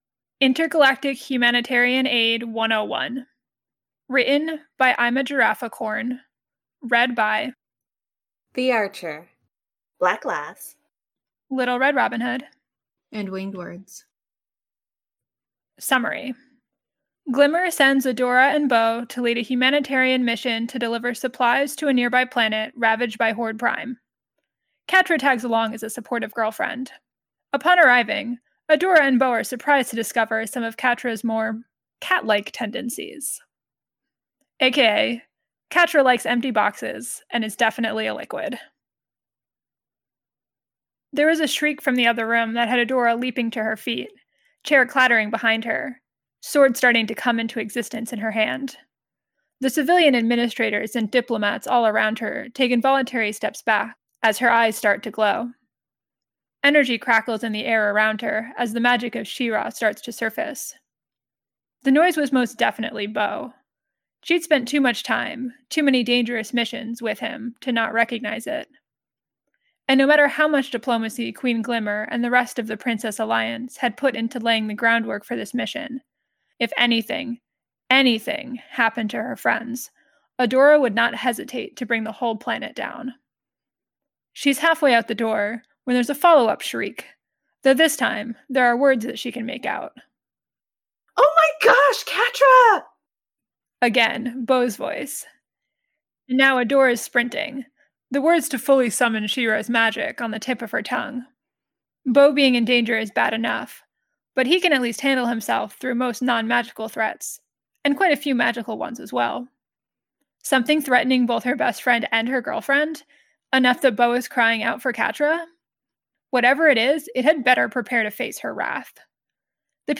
Podfic with improv scenes: download mp3: here (r-click or press, and 'save link') [58 MB, 01:12:59]